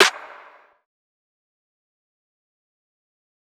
TC2 Snare 1.wav